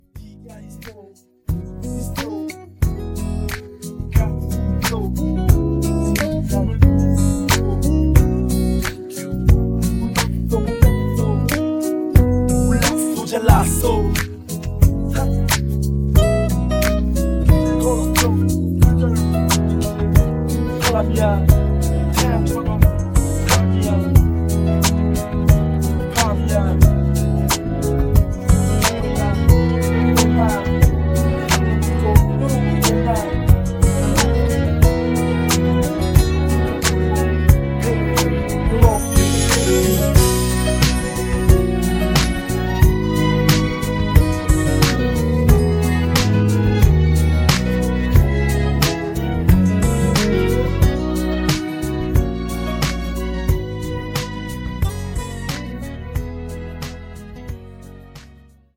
음정 -1키 4:13
장르 가요 구분 Voice MR